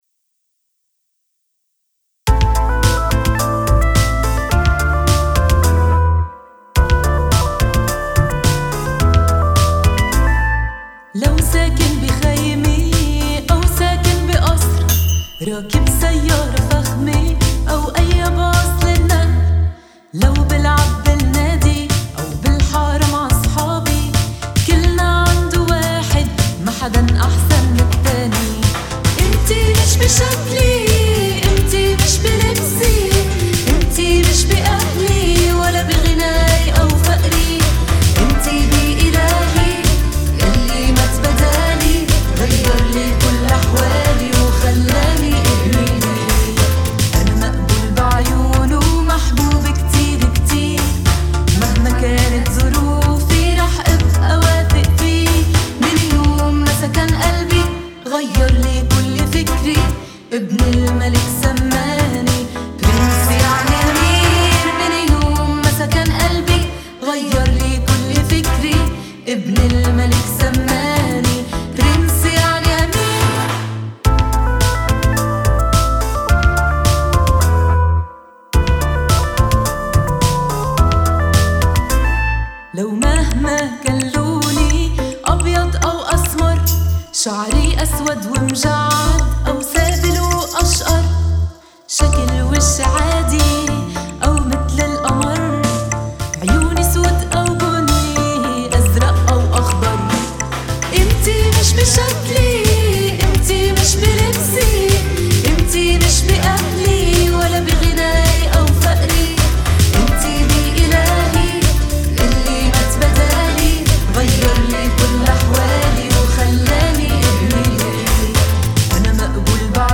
ترنيمة برنس يعني أمير